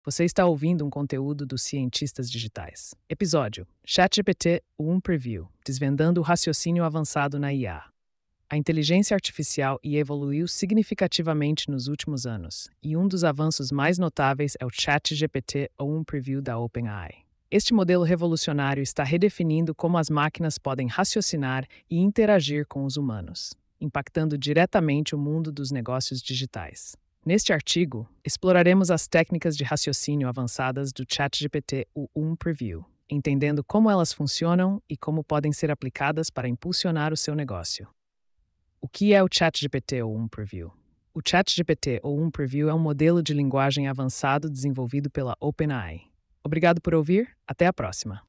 post-2530-tts.mp3